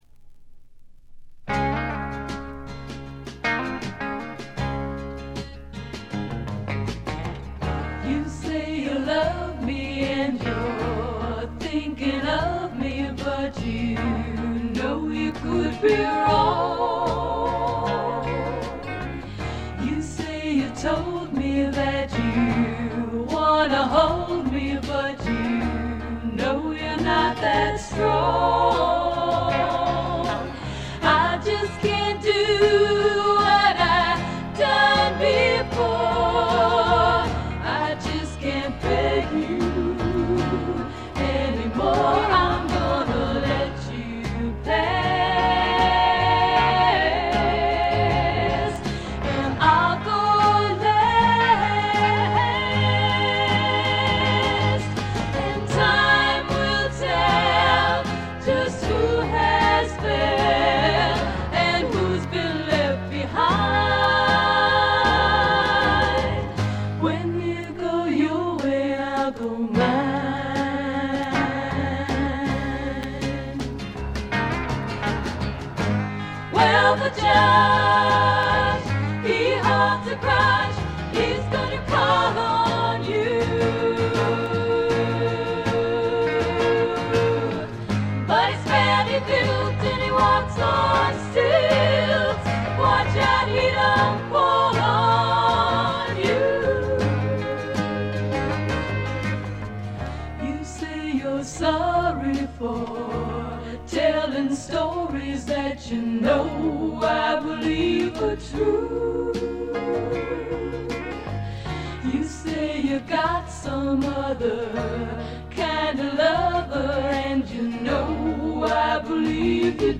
3人娘のヴォーカル・ユニット
試聴曲は現品からの取り込み音源です。
acoustic rhythm guitar